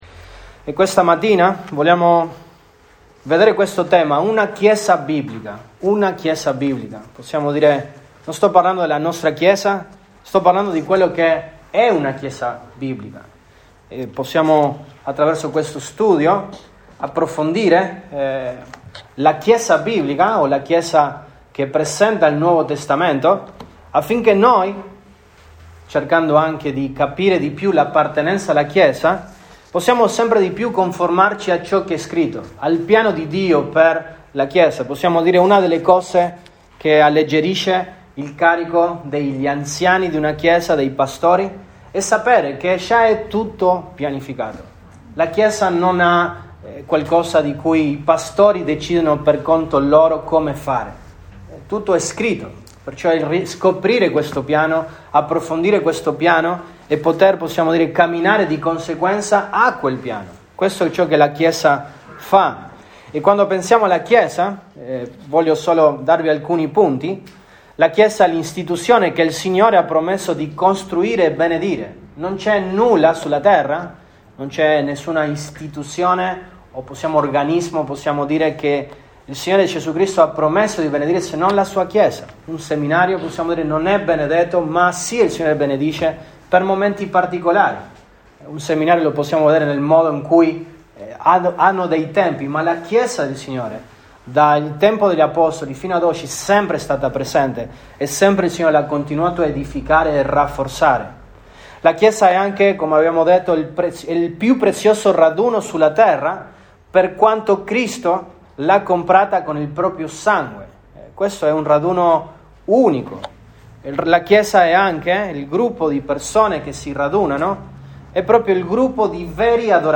Nov 21, 2021 Una chiesa biblica MP3 Note Sermoni in questa serie Una chiesa biblica.